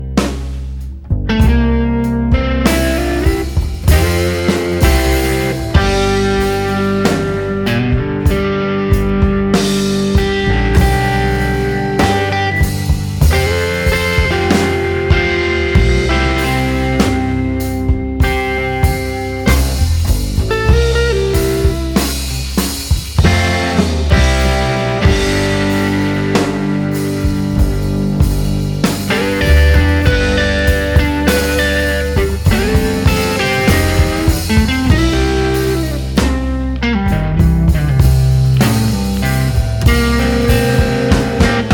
an extended jam with just the trio - guitar, bass and drums.